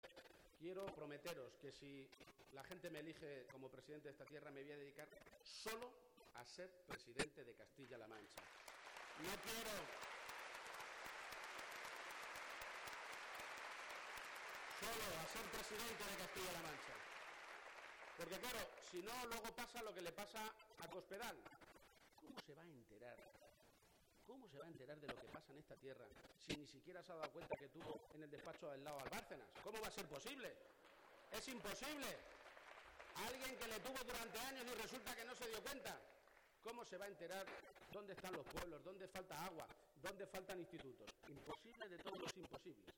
García-Page hacía esta mañana en el Teatro Auditorio de Cuenca la presentación de su candidatura.